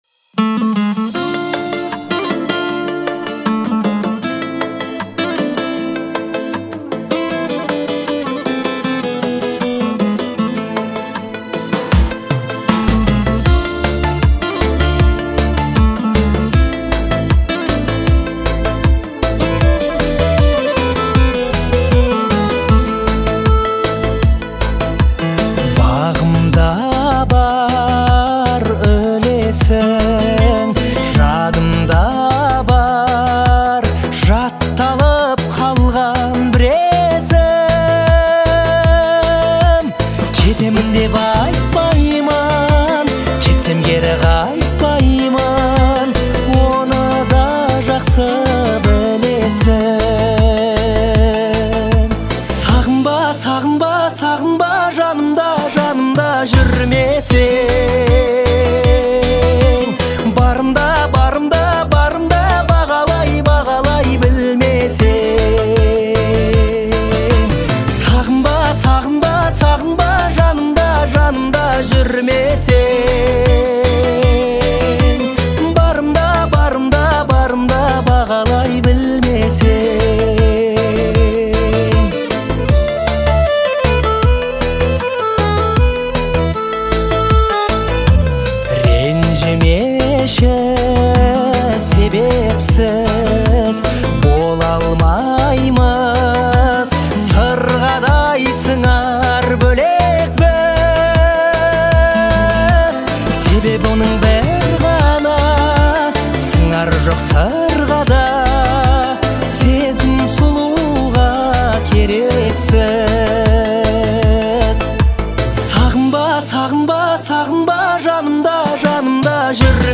относится к жанру казахской поп-музыки.